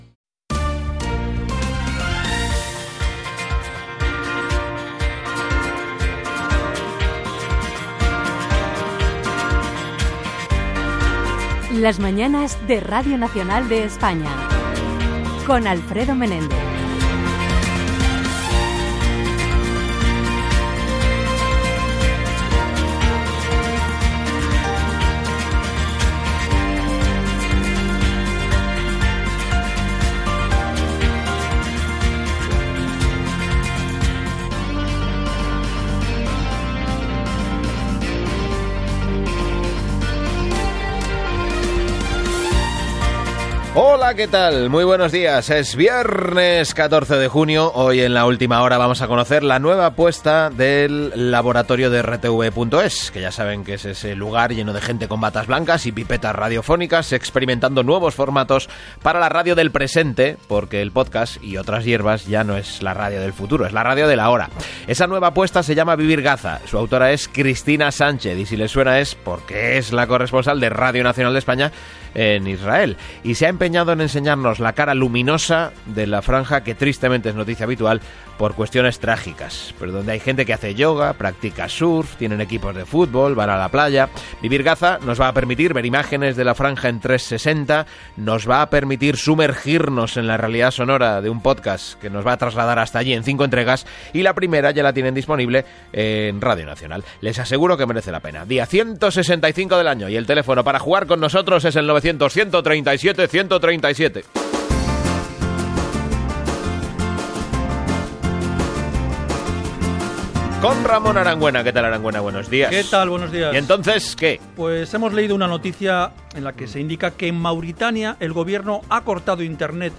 Indicatiu, data, continguts de l'hora, trucades de l'audiència explicant càstigs rebuts
Info-entreteniment